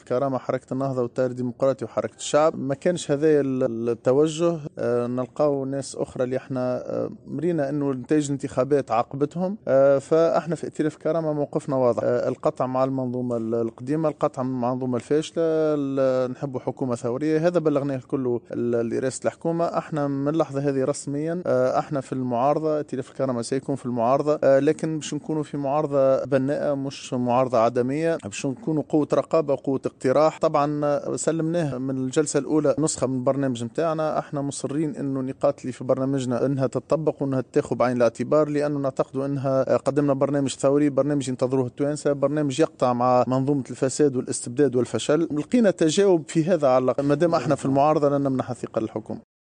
أعلن رئيس كتلة ائتلاف الكرامة سيف الدين مخلوف في تصريح لمراسلة "الجوهرة اف أم"، عقب لقائه مساء اليوم رئيس الحكومة المكلف الحبيب الجملي، أن كتلته ستكون في المعارضة بمجلس نواب الشّعب.